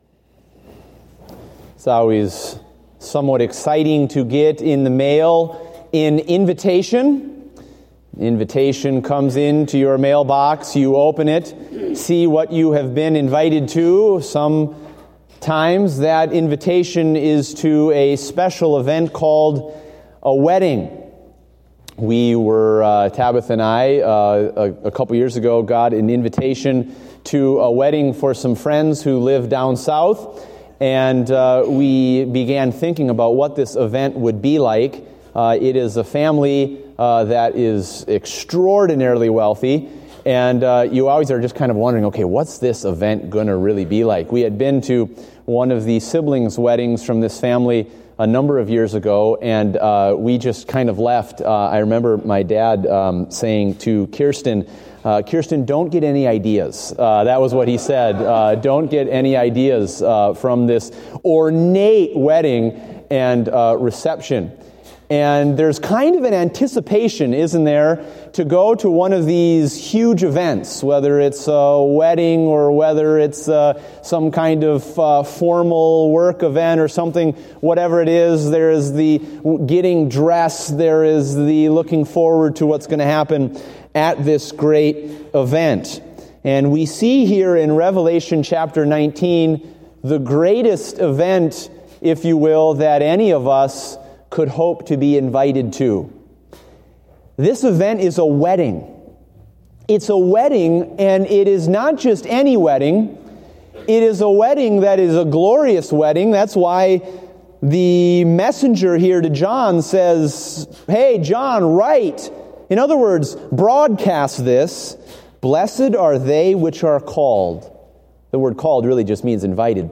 Date: January 10, 2016 (Morning Service)